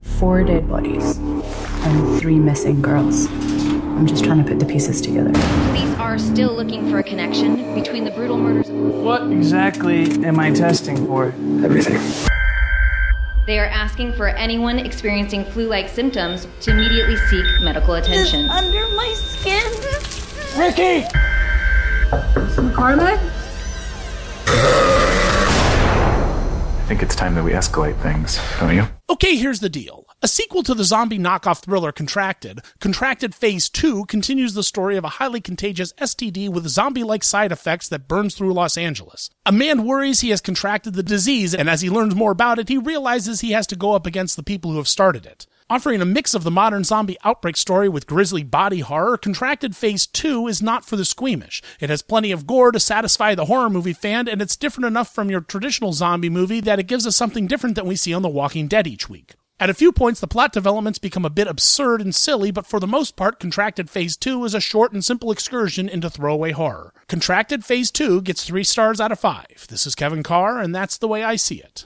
‘Contracted: Phase II’ Movie Review